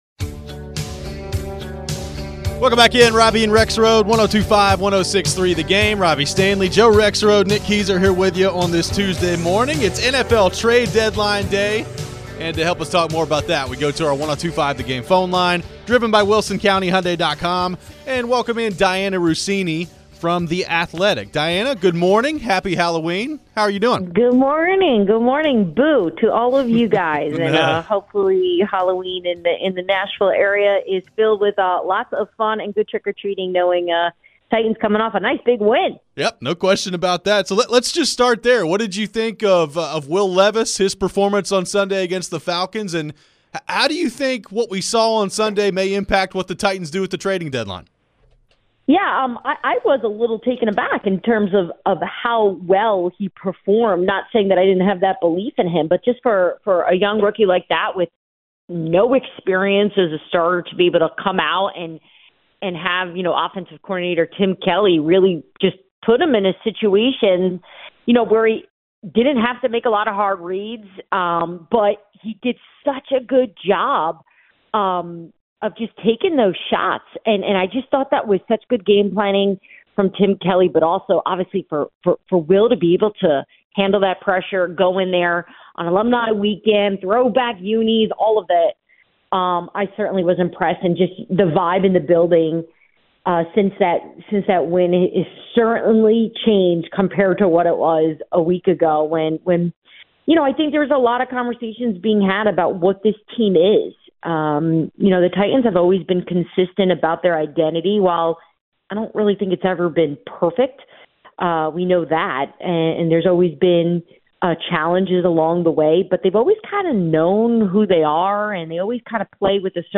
Dianna Russini Interview (10-31-23)
Senior NFL Insider from The Athletic Dianna Russini joined the show ahead of today's NFL Trade Deadline. What does Dianna think the Titans could do today?